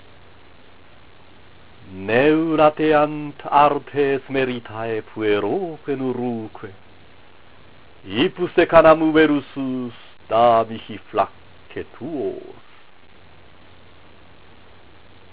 朗読15-16行